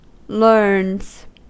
Ääntäminen
Ääntäminen US Haettu sana löytyi näillä lähdekielillä: englanti Learns on sanan learn yksikön kolmannen persoonan indikatiivin preesens.